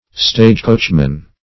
Stagecoachmen - definition of Stagecoachmen - synonyms, pronunciation, spelling from Free Dictionary